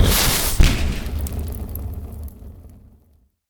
fire-bolt-002-15ft.ogg